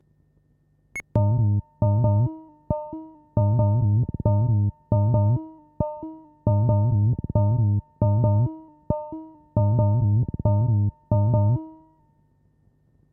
塔巴拉机" ADA H
标签： 机器 塔布拉
声道立体声